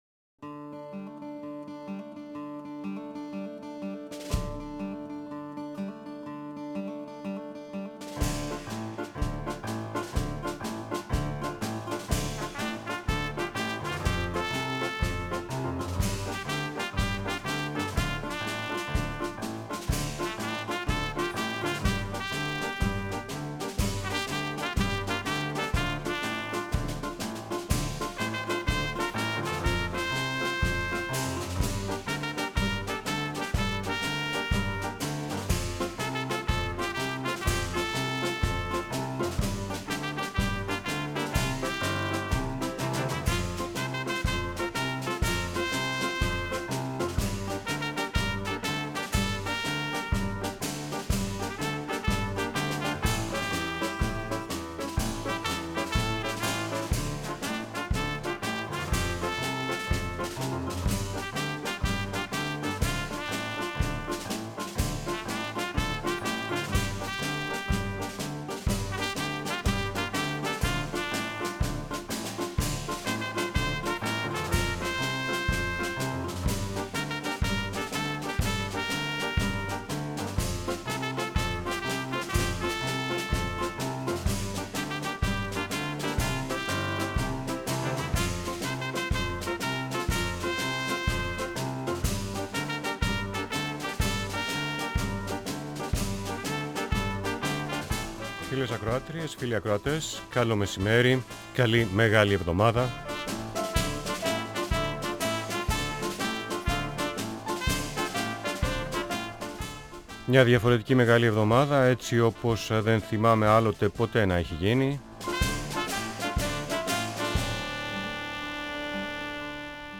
“Τεχνηέντως”, Μ. Δευτέρα,13/4, λίγο μετά τη 1 μ.μ. Μαζί μας τλφνκα ο συγγραφέας Ισίδωρος Ζουργός, μας αφηγείται μια δική του ιστορία.